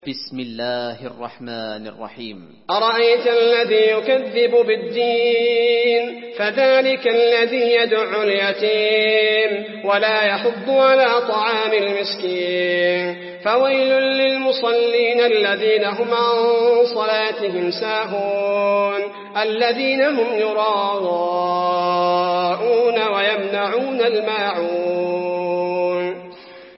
المكان: المسجد النبوي الماعون